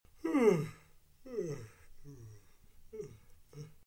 yawn2.wav